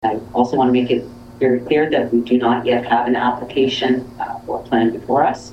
feb-24-lisa-mcgee-mayors-report-3.mp3